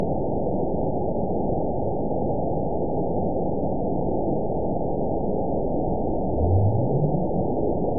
event 920525 date 03/28/24 time 22:06:09 GMT (1 year, 1 month ago) score 9.31 location TSS-AB02 detected by nrw target species NRW annotations +NRW Spectrogram: Frequency (kHz) vs. Time (s) audio not available .wav